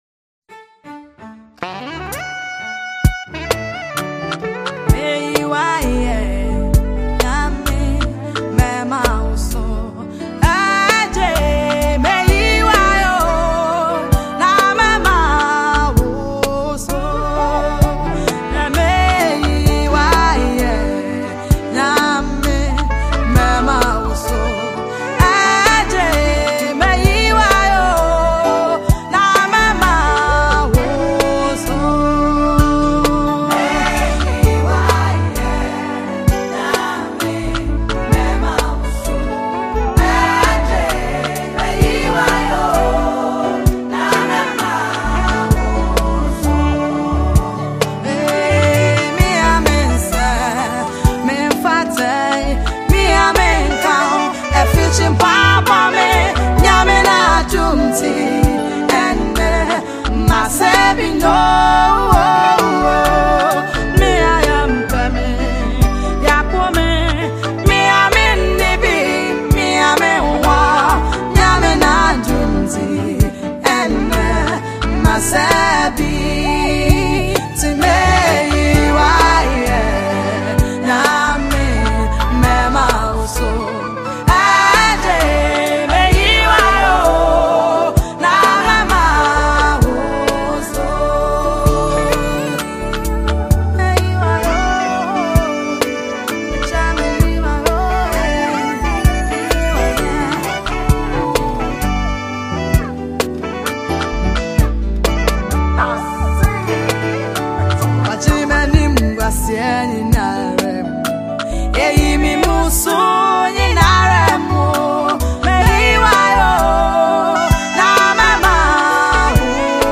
GOSPEL MUSIC
super spiritual uplifting track
Genre: Gospel